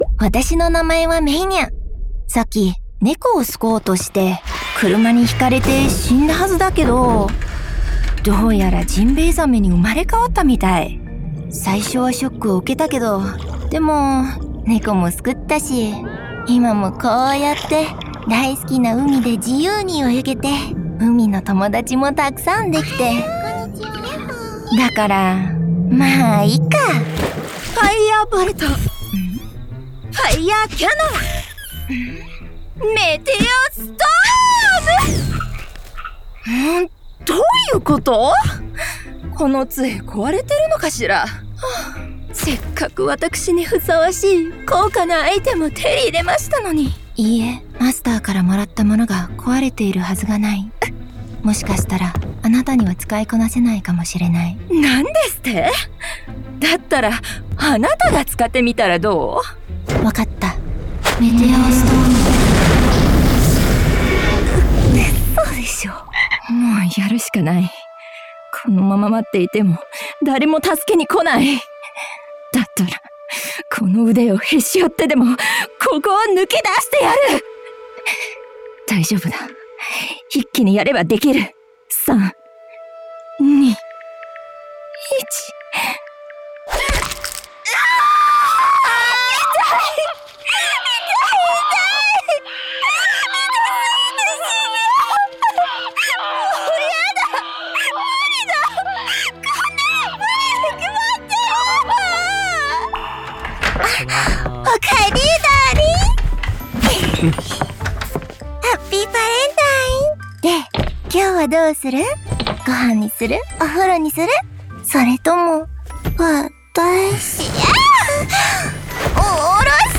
Voice Demo Reels
Character Demo - Japanese